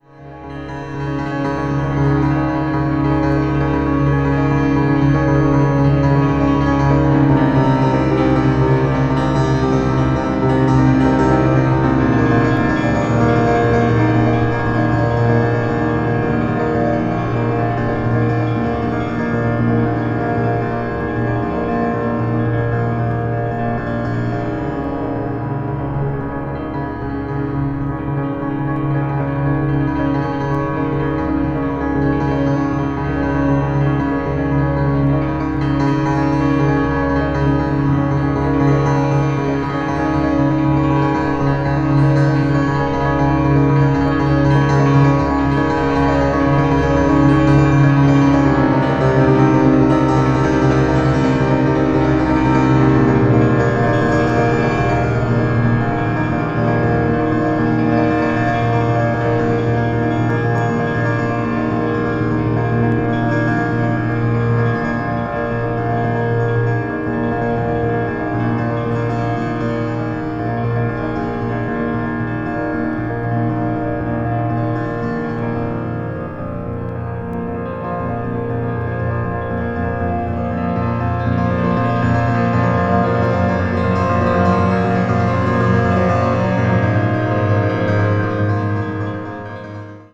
unique minimalist composition